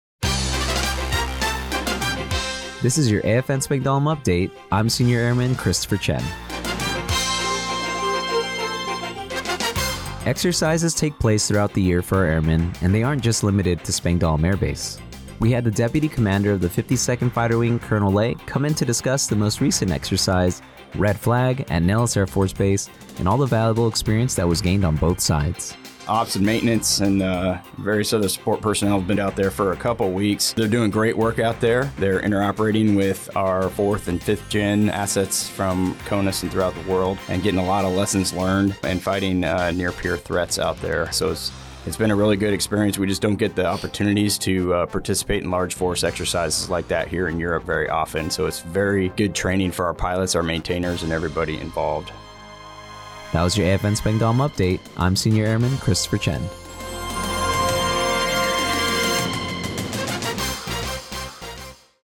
The following was the radio news report for AFN Spangdahlem for 05 Aug 2024/